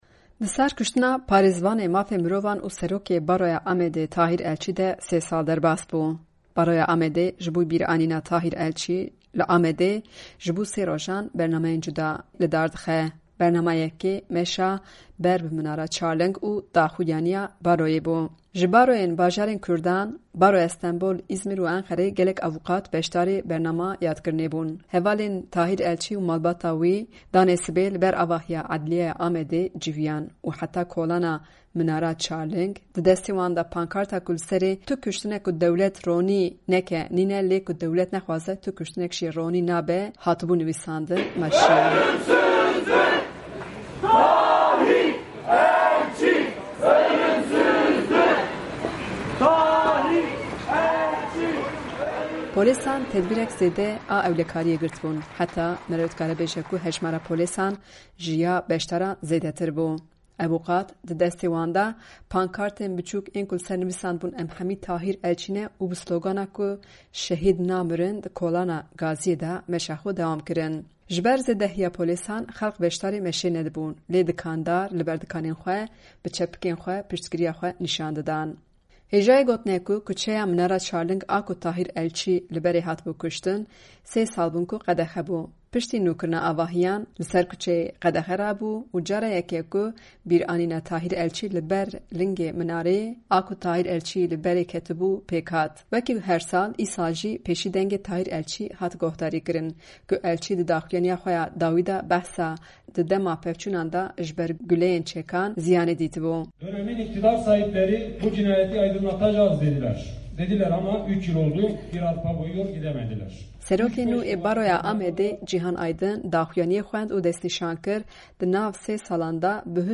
Çend awuqatên li Amedê hêst û hizirên xwe yên ser Elçî bi guhdarên me re parve kirin.